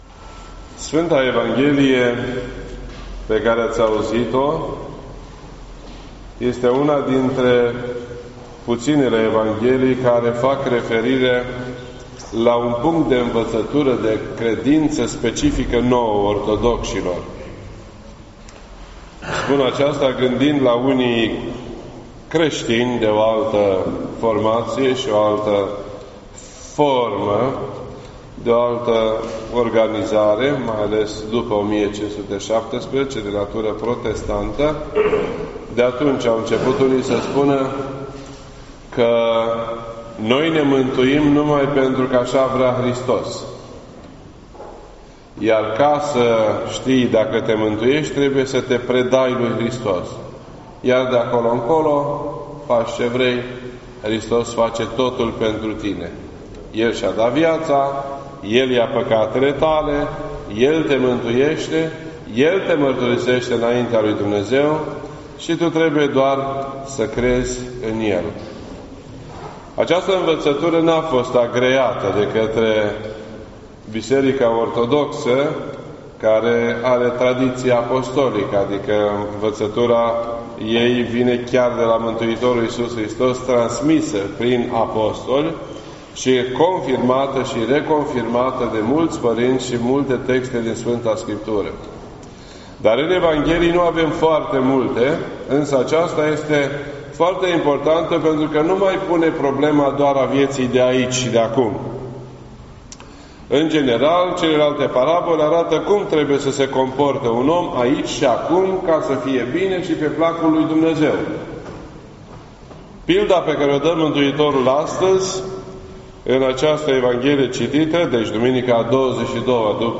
This entry was posted on Sunday, November 3rd, 2019 at 4:25 PM and is filed under Predici ortodoxe in format audio.